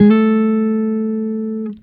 Guitar Slid Octave 10-A2.wav